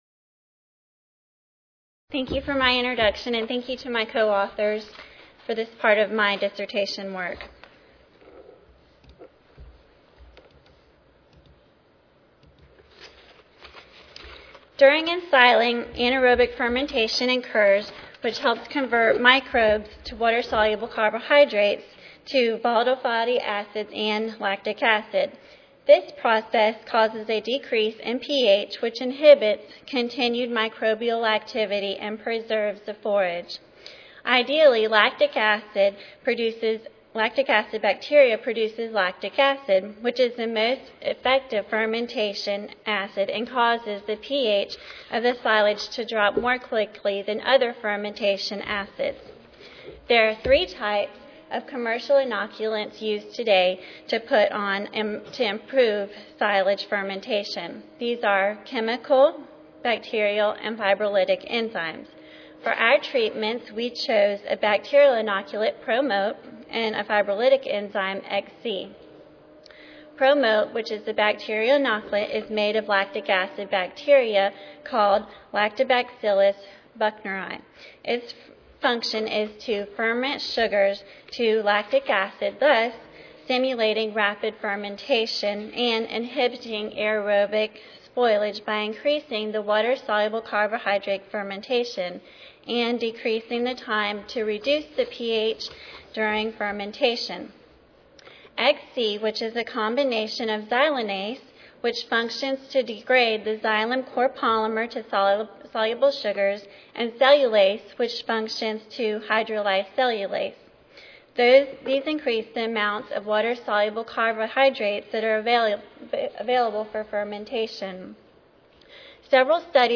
Abstract: Inoculant Application to Improve the Nutritive Value and Ensiling Characteristics of Sorghum Silage. (ASA, CSSA and SSSA Annual Meetings (San Antonio, TX - Oct. 16-19, 2011))